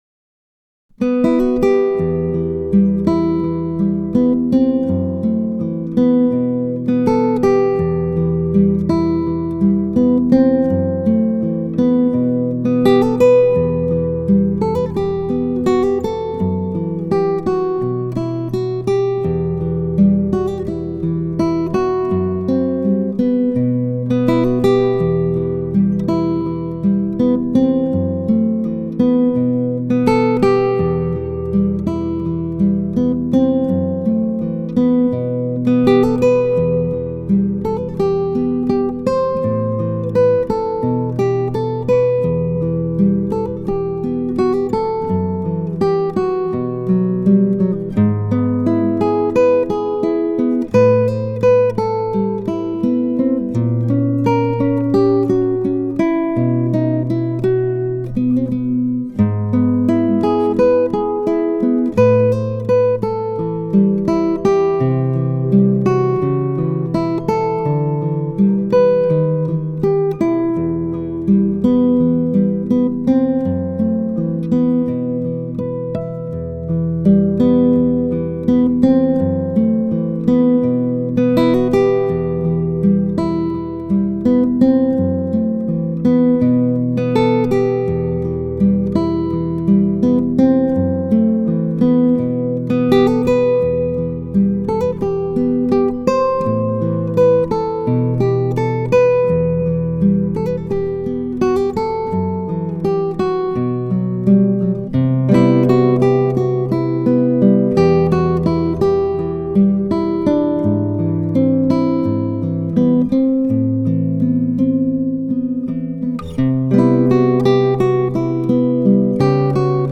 موسیقی کنار تو
سبک موسیقی بی کلام , آرامش بخش , خواب , گیتار